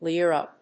/lírə(米国英語), líərə(英国英語)/